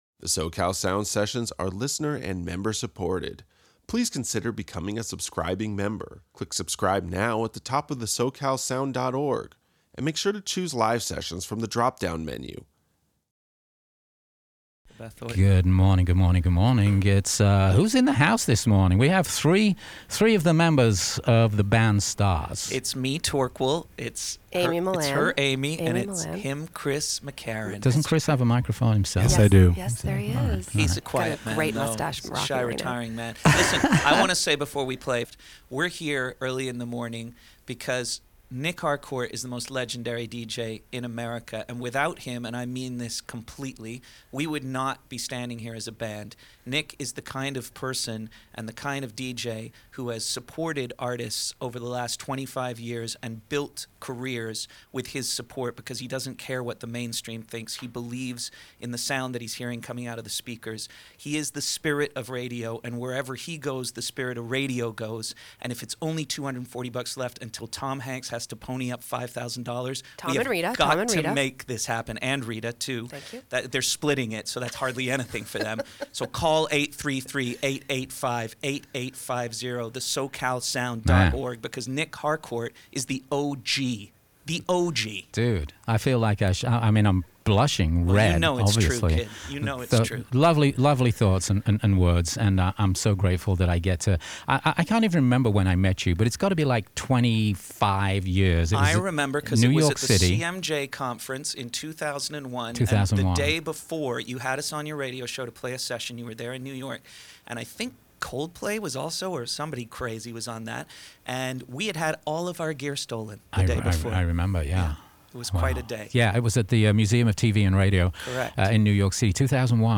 Los Angeles's public alternative rock radio.